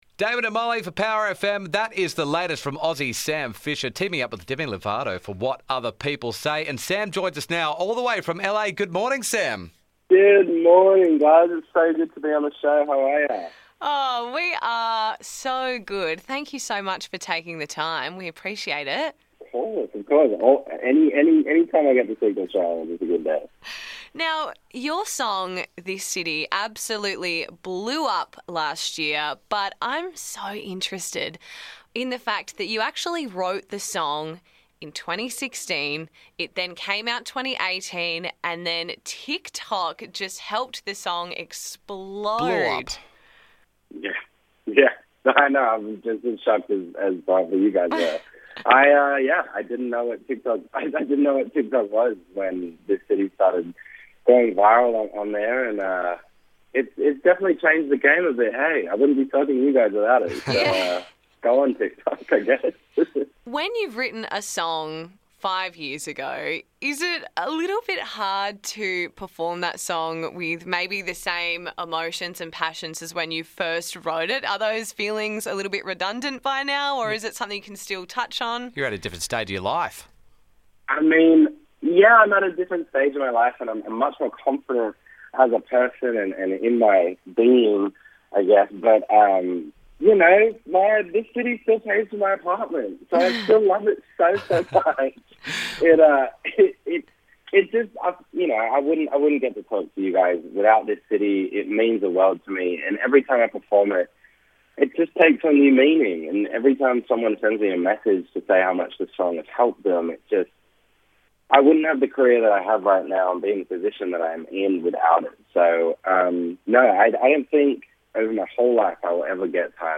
This City was the huge breakout hit for Aussie Sam Fischer. Now he's joined forces with Demi Lovato for new single What Other People Say and to celebrate he joins the show from LA!